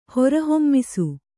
♪ hora hommisu